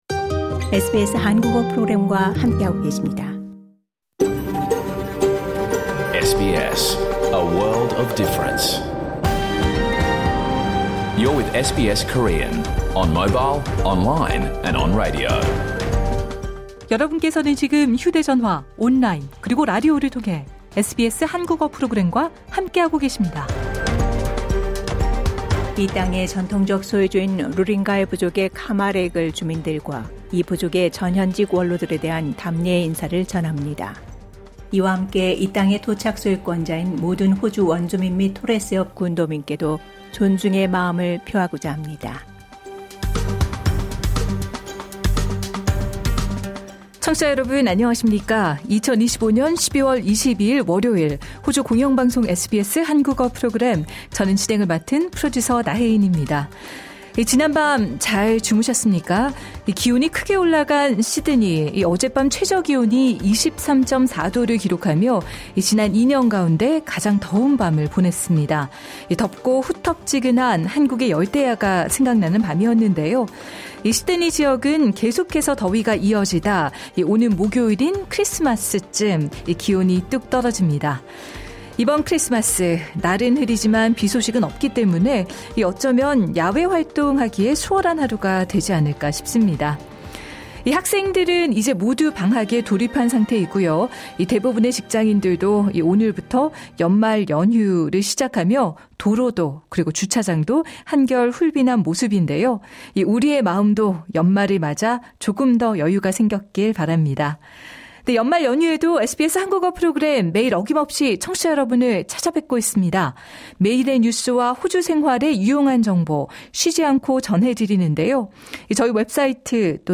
2025년 12월 22일 월요일에 방송된 SBS 한국어 프로그램 전체를 들으실 수 있습니다.